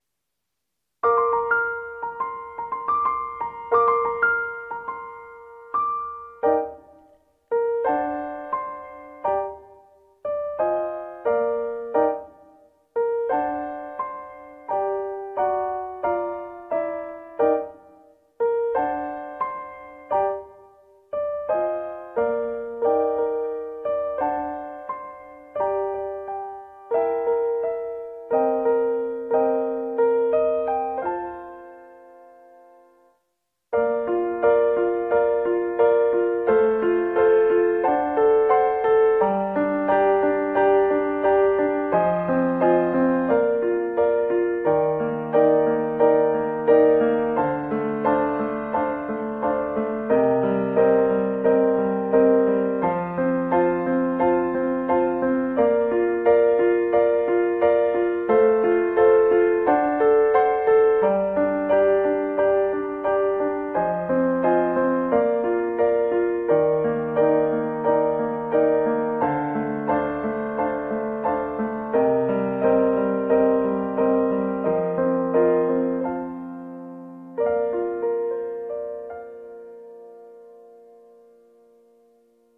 岐阜弁で告白 コラボ用